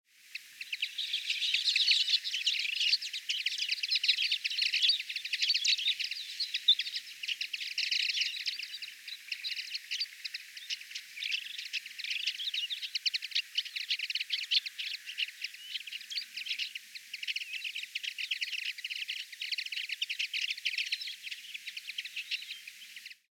Kenderike (Linaria cannabina) hangja
A kenderike (Linaria cannabina) hangja összetett, dallamos, és jellegzetesen csicsergő. Éneke nem annyira harsány, mint egy feketerigóé vagy seregélyé, de nagyon változatos és szép csilingelő hangzású. Gyakoriak benne az ismétlődő trillák, csattogó és füttyszerű motívumok, valamint a szökkenő-szerű hangváltások.
A hívóhang általában rövid, csipogásszerű, és a madarak közötti távolság csökkentésére szolgál.